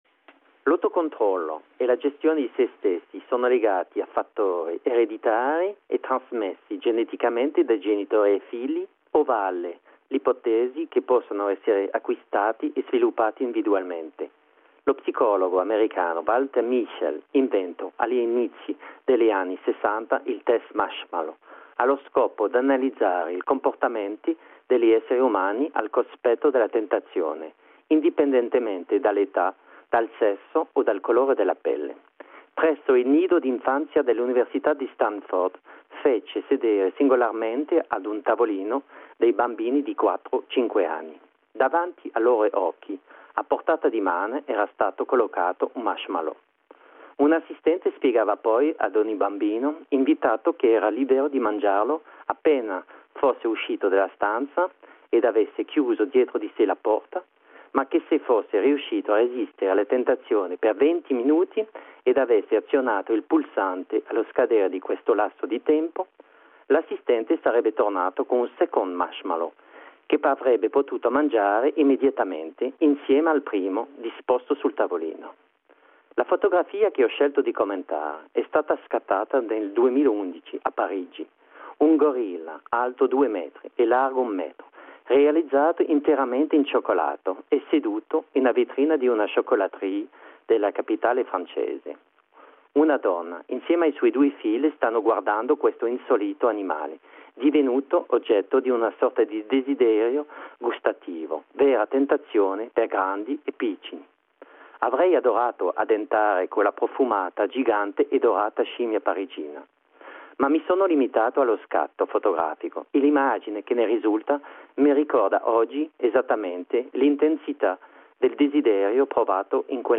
A radio comment about the image “Mashmallow and gorilla”.